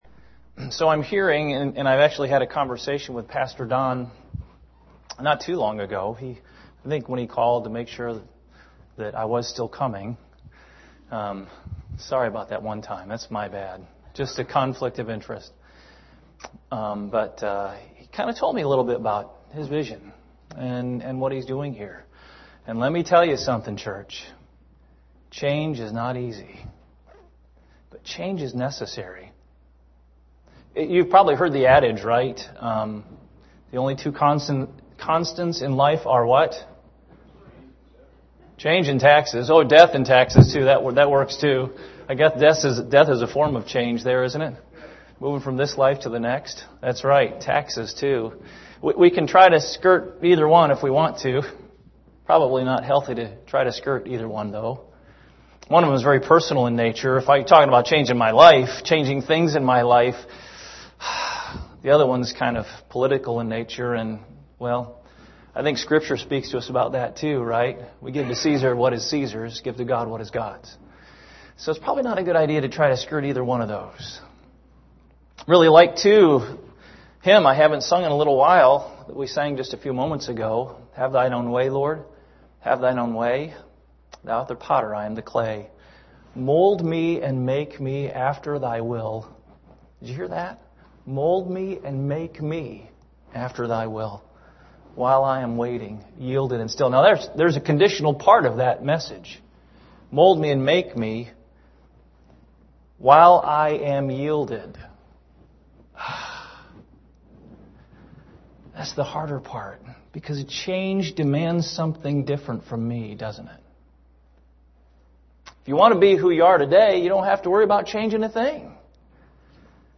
Topical Sermons
Morning Sermon